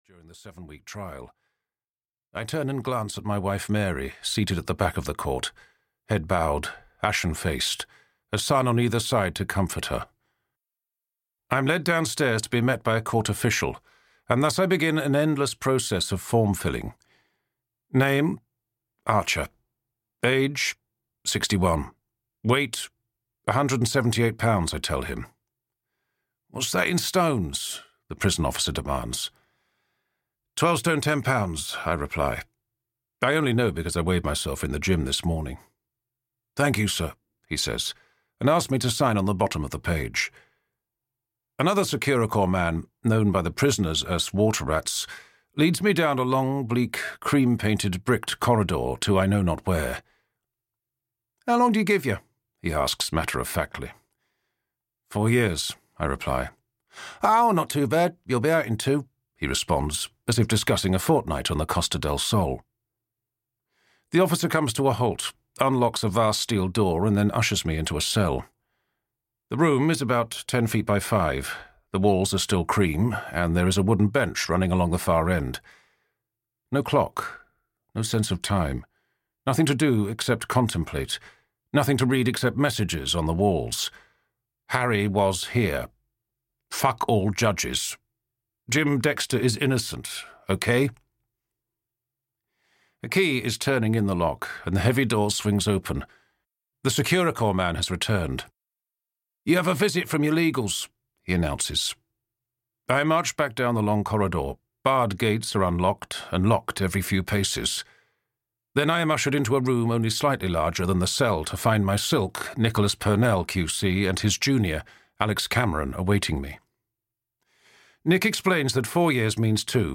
A Prison Diary I - Hell (EN) audiokniha
Ukázka z knihy